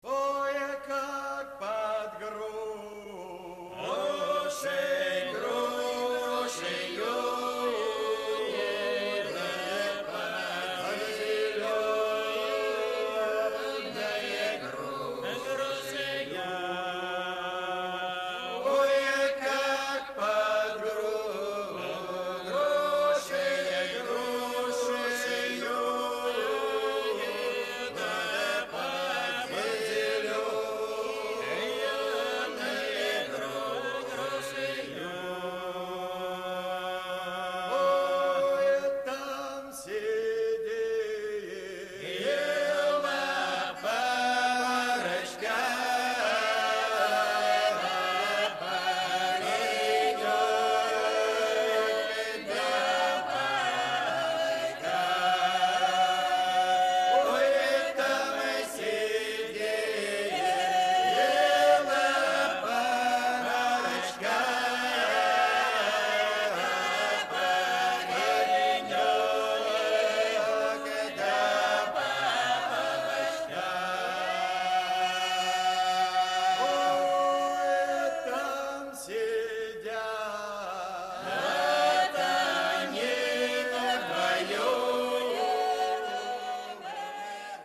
Kazak lingering song